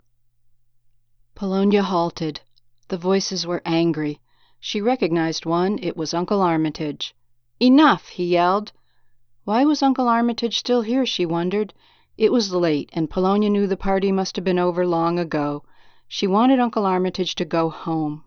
There’s a wall power hum in there.
I tried it with the Gain turned all the way up (green light flashing) but I thought that made it sound like my mouth was too close to the mike so I reduced it down a bit.